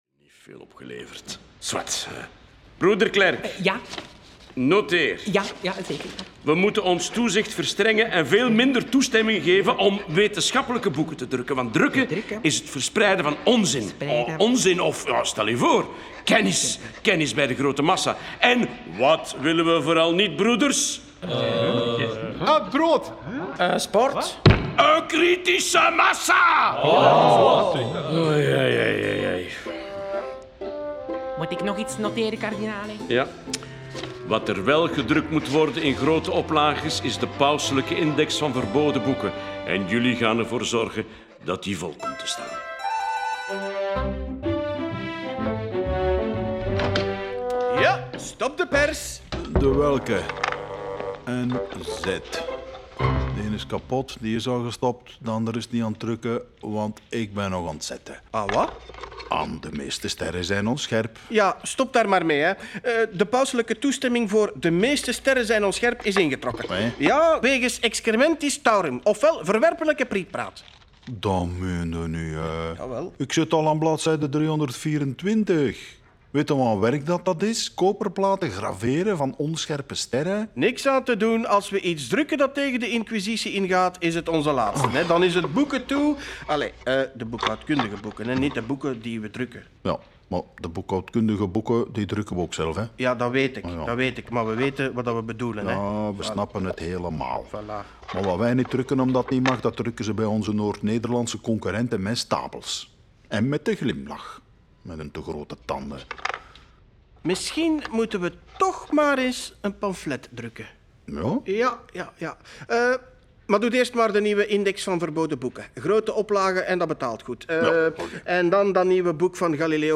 In dit experimenteel interplanetair hoorspel volgen we Galileo’s opgang en de opwinding die hij bij studenten, wetenschappers, drukkers en enthousiaste biechtvaders veroorzaakte.